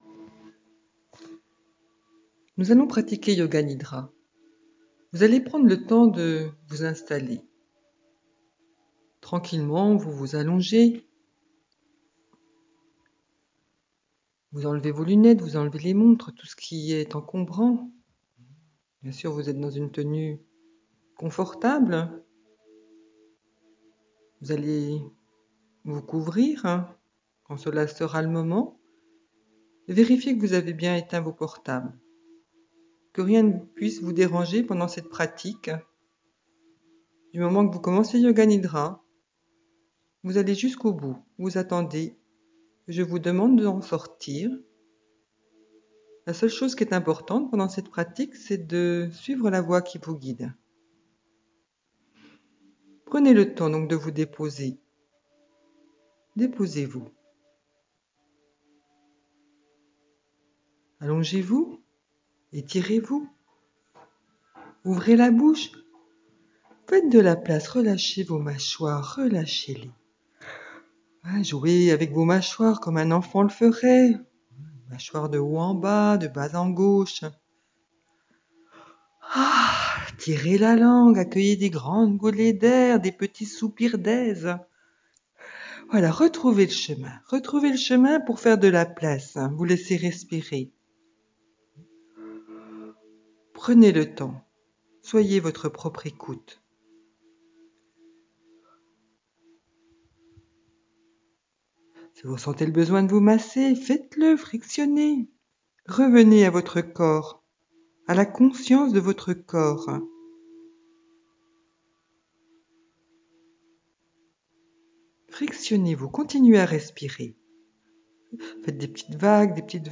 yoga-nidra-Ce-qui-est-essentiel-pour-moi-aujourdhui-1.mp3